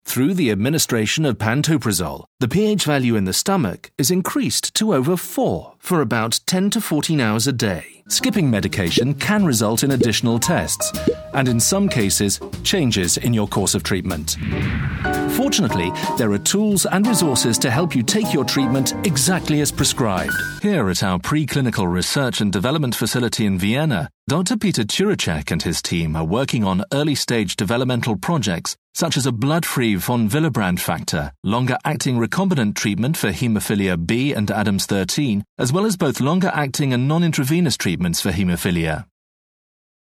articulate, classic, contemporary, British English, standard BBC
Sprechprobe: Sonstiges (Muttersprache):
Warm, intelligent and compelling British voice for all audio applications